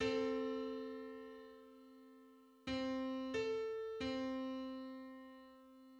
Just: 217/128 = 913.86 cents.
Public domain Public domain false false This media depicts a musical interval outside of a specific musical context.
Two-hundred-seventeenth_harmonic_on_C.mid.mp3